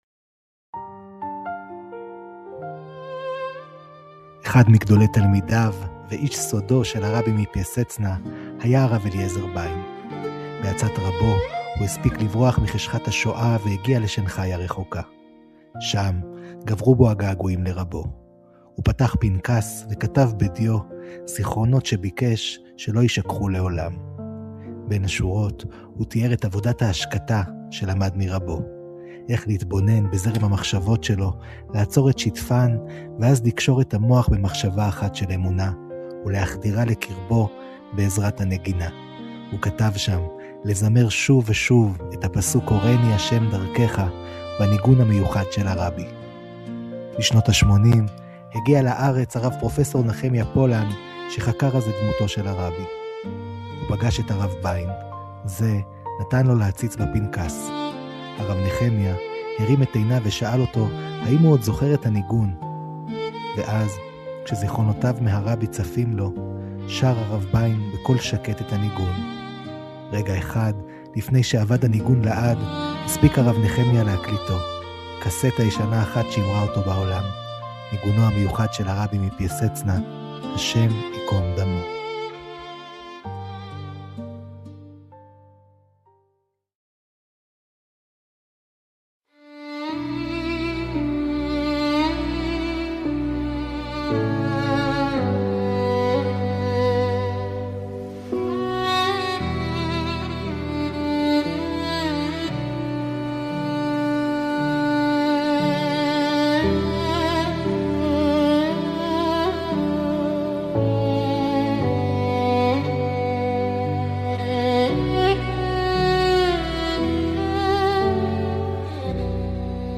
ניגון מדיטטיבי שהרבי לימד את חסידיו.